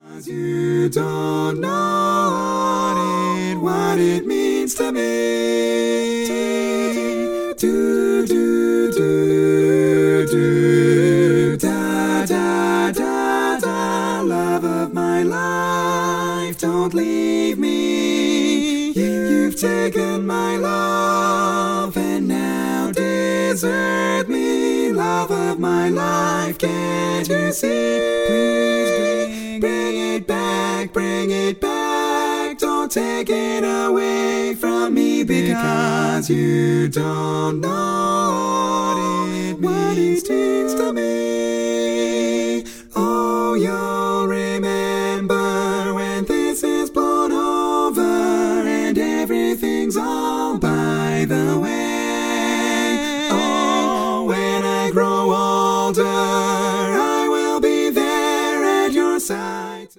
Female